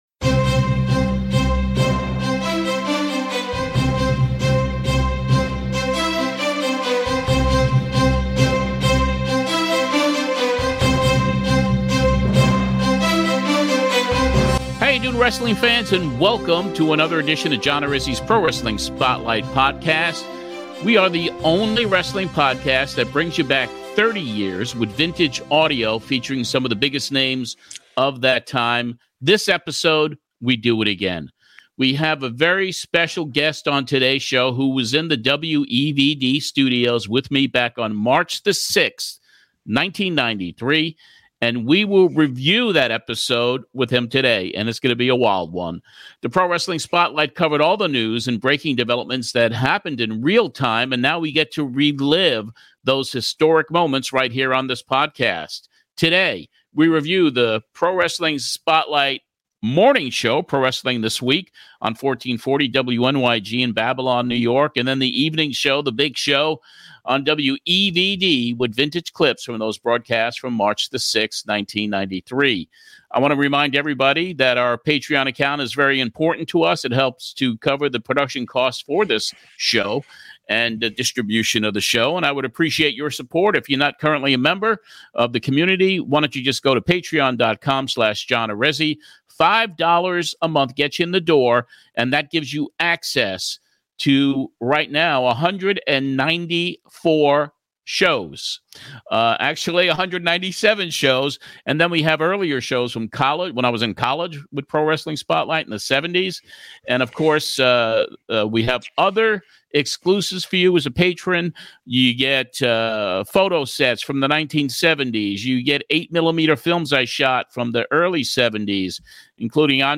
Special guest this podcast is KONNAN, who reviews the episode from 30 years ago with us. Other guests on that March 6th, 1993 show include TERRY FUNK, and a surprise call in from THE HONKY TONK MAN! Honky gets blasted by Funk in (a SHOOT!) in a wild ending of the show.